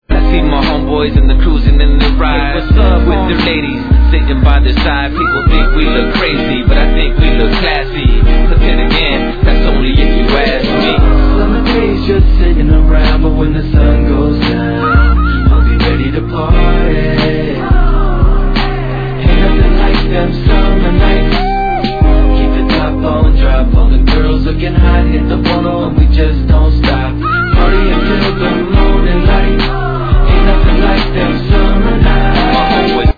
R&Bを思わす緩?いギターのフレーズとレイドバックしたリズム
Tag       CHICANO/TALK BOX WEST COAST